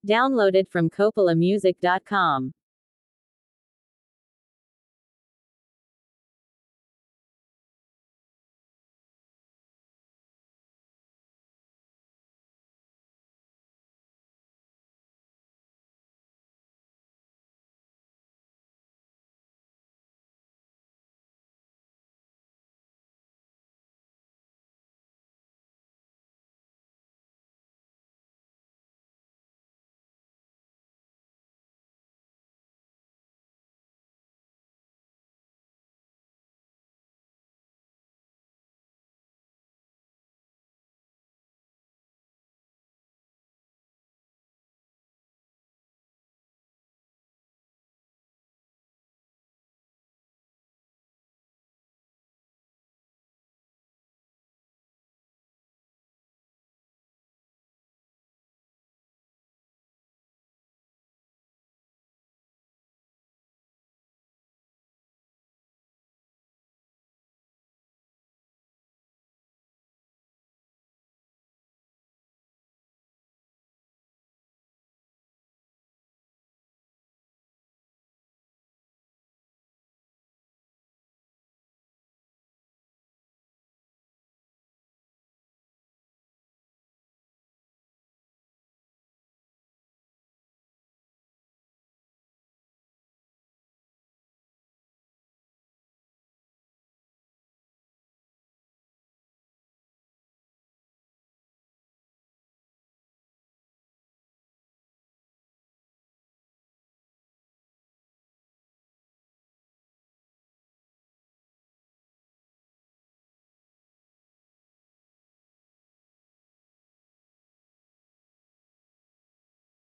deeply reflective and emotionally charged song
signature smooth and soulful delivery
a more raw and honest approach